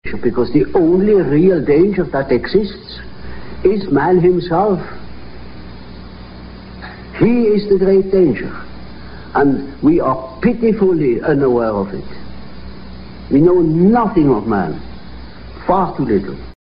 Část rozhovoru s C. G. Jungem v televizním
s moderátorem Johnem Freemanem, z října roku 1959.